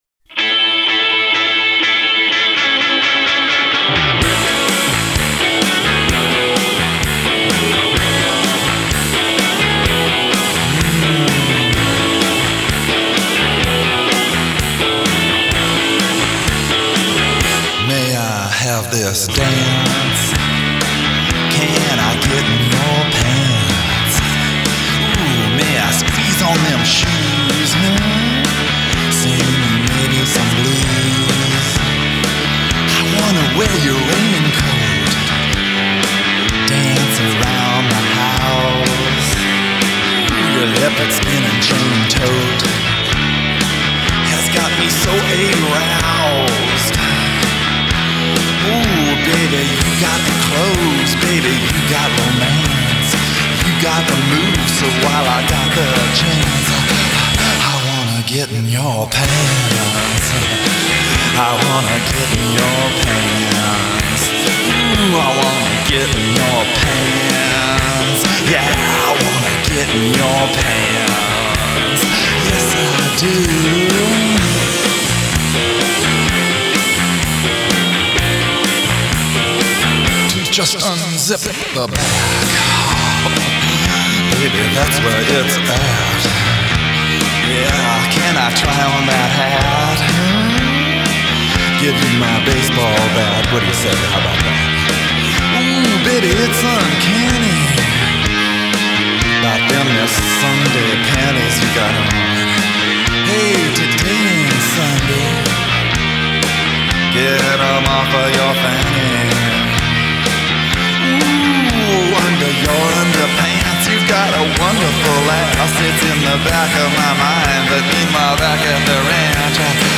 The twang’s the thang, baby!